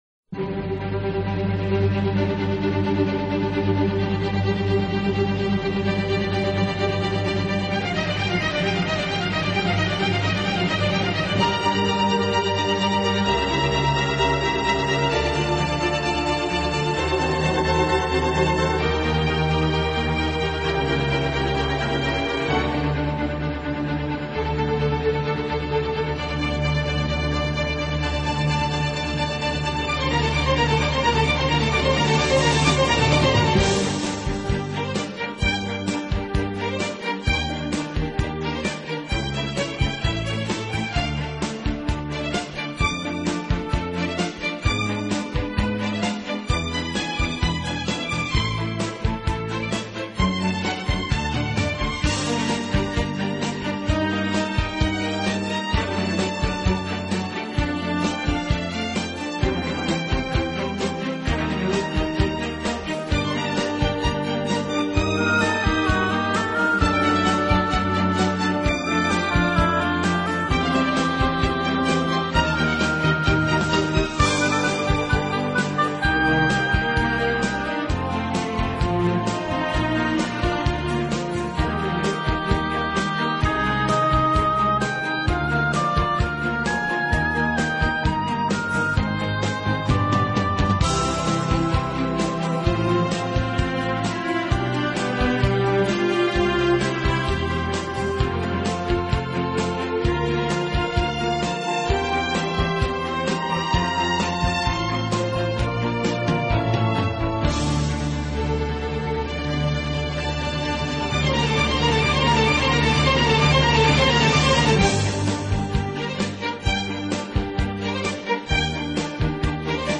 音乐类型：跨界融合 fusion
音乐风格：Neo Classical，室内乐